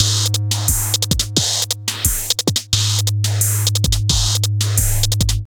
___SINELOOP 1.wav